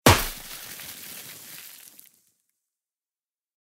Confetti 1.ogg